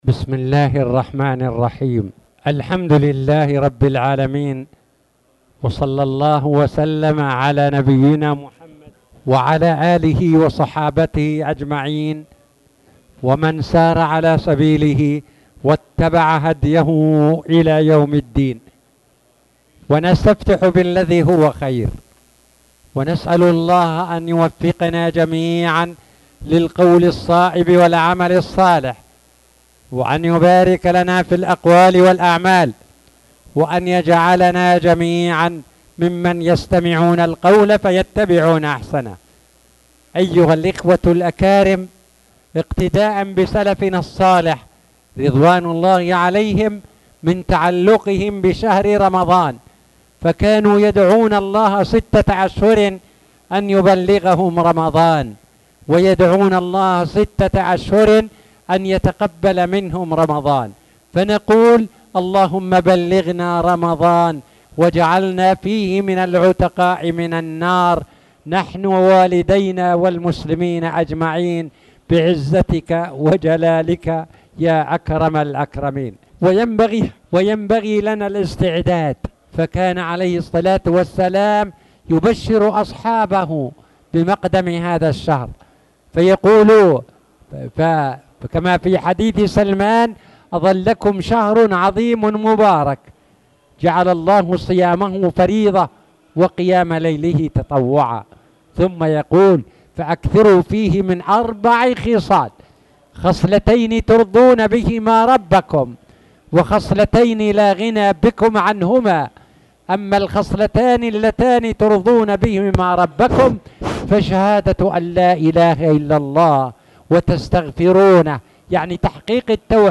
تاريخ النشر ١٤ شعبان ١٤٣٨ هـ المكان: المسجد الحرام الشيخ